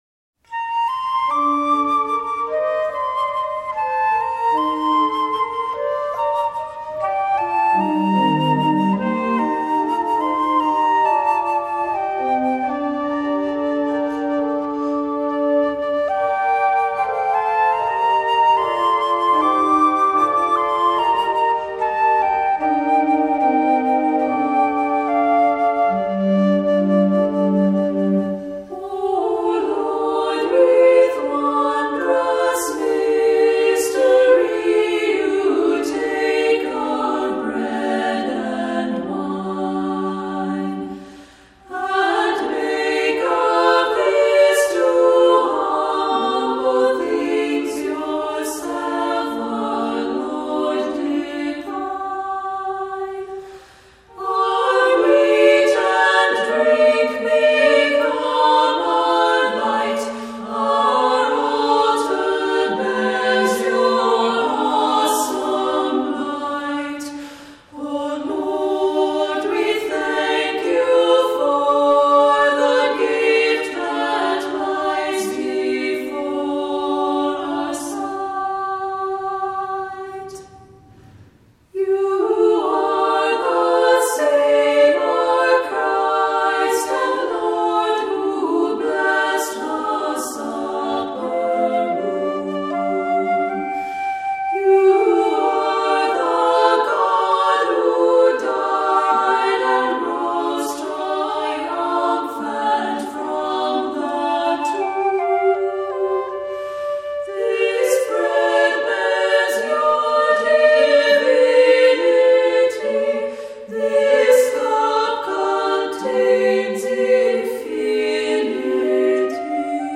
Voicing: Three-part choir; opt. assembly